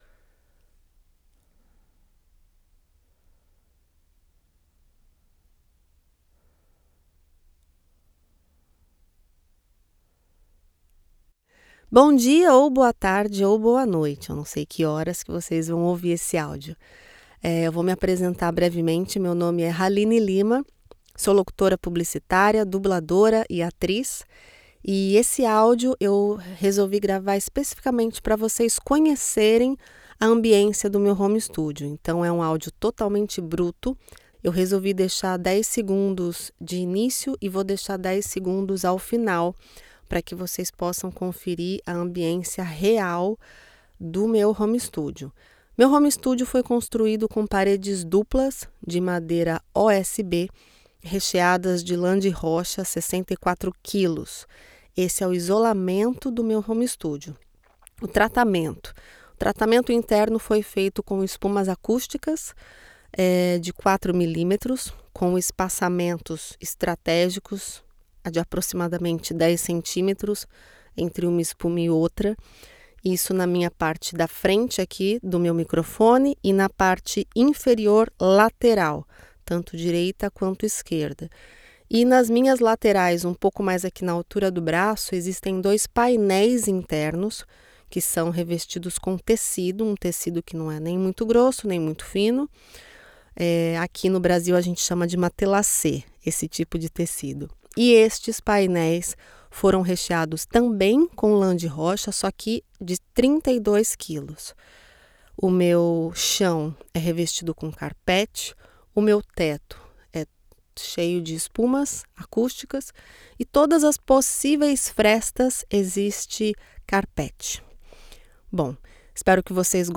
Female
Approachable, Assured, Bright, Cheeky, Conversational, Corporate, Deep, Friendly, Natural, Posh, Smooth, Warm, Young
My accent is considered neutral, with a soft memory of São Paulo prosody.
Warm, youthful, and highly professional, my voice delivers a natural and conversational performance with a neutral accent.
Microphone: AKG P220